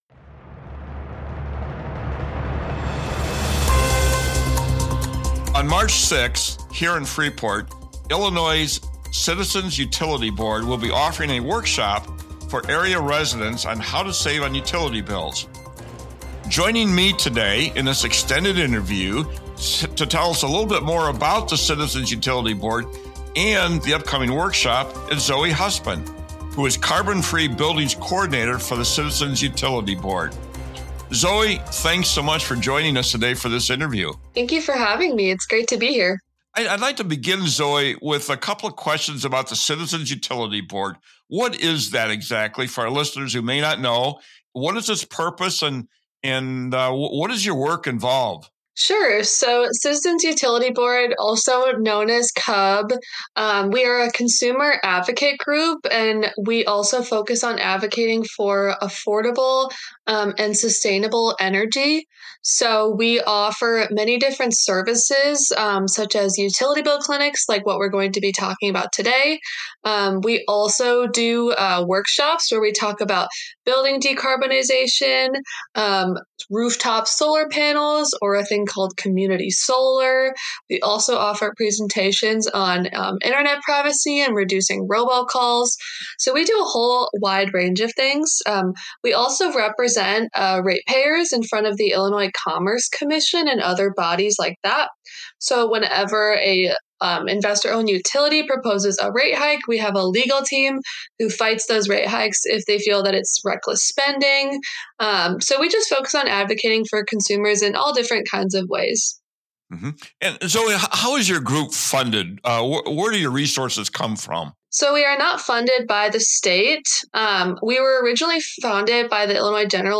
Freepod - Freepod Interview: Citizens Utility Board